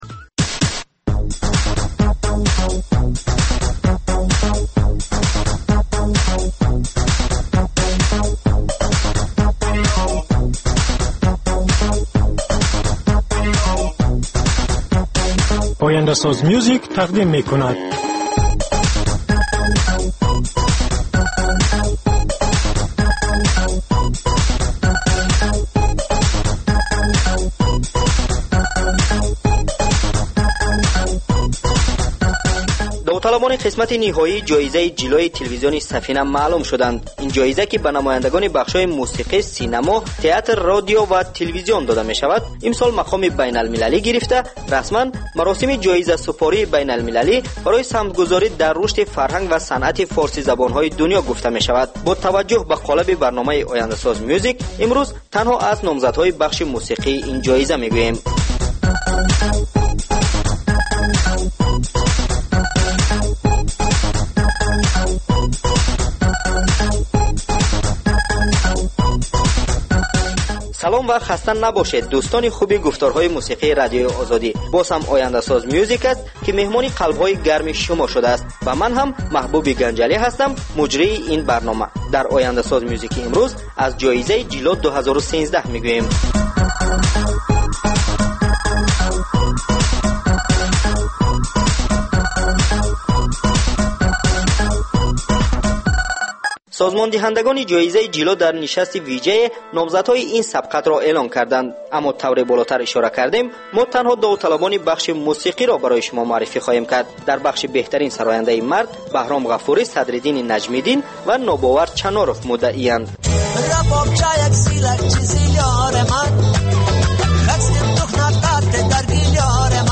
Ахбори фарҳангӣ, гуфтугӯ бо овозхонони саршинос, баррасии консерт ва маҳфилҳои ҳунарӣ, солгарди ходимони ҳунар ва баррасии саҳми онҳо.